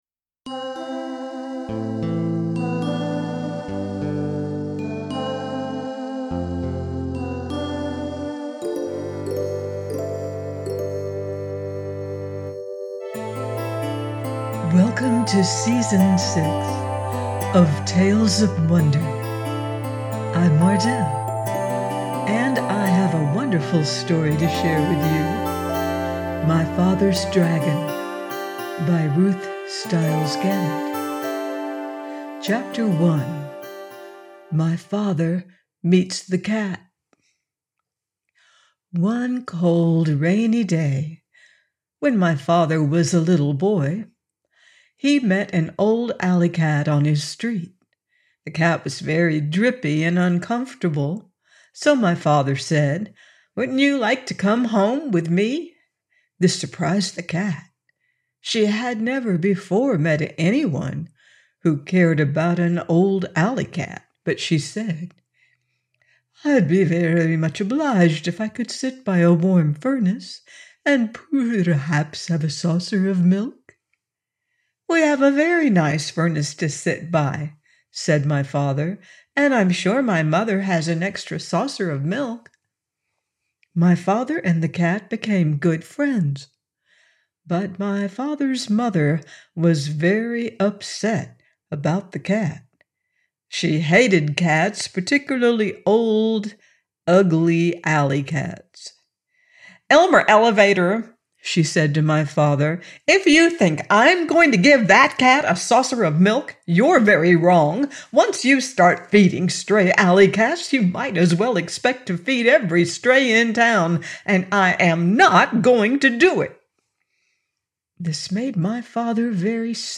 My Father’s Dragon -01- by Ruth Styles Gannett - Audiobook